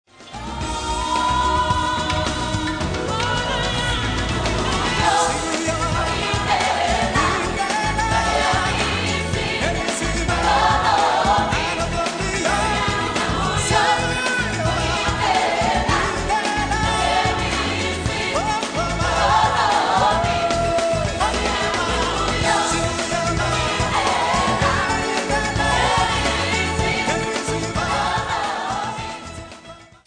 Its rousing, bouncy, and optimistic beat is irresistable.